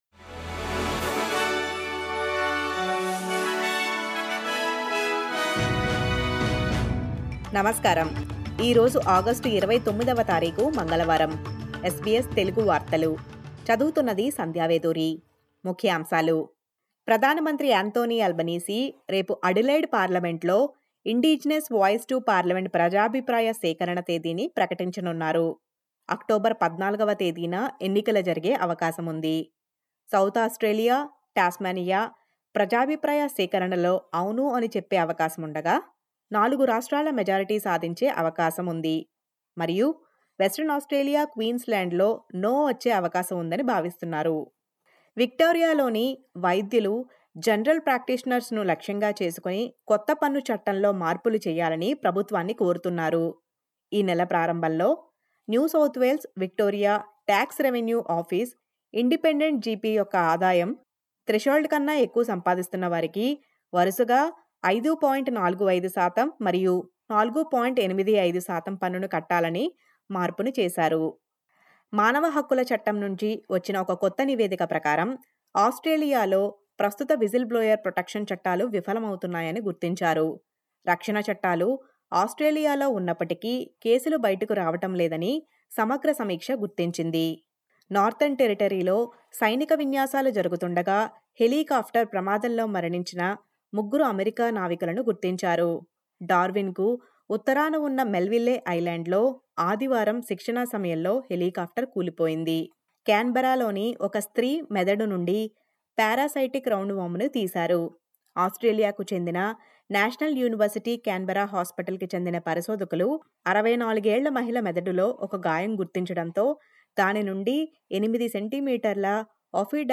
SBS Telugu వార్తలు